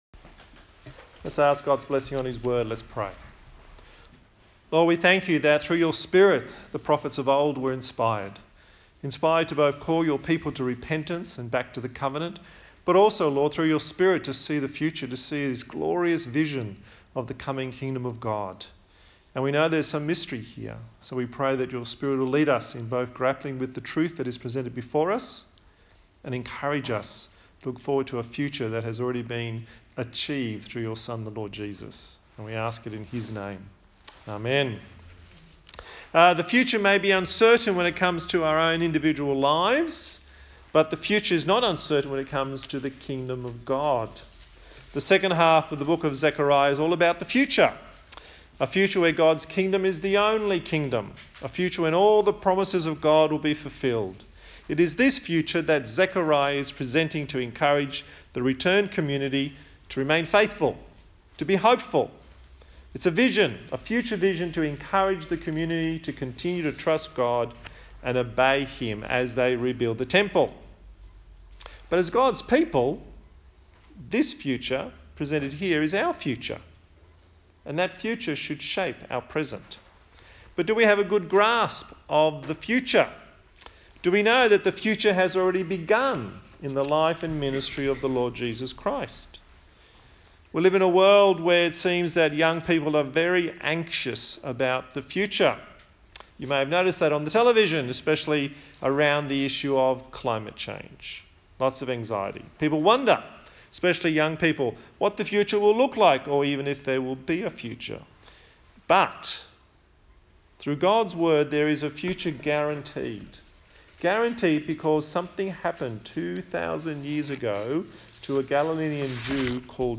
A sermon in the series on the book of Zechariah